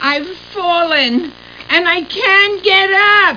Amiga 8-bit Sampled Voice
1 channel